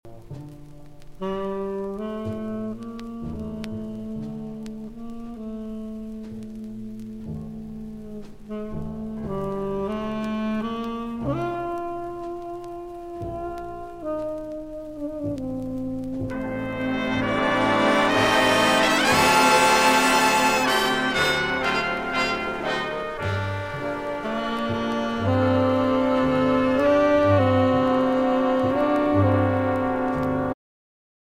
The guest musician was Lou Marini.
Jazz
trumpet
trombones
alto saxophone
tenor saxophone
baritone saxophone
piano
bass
percussion
guitar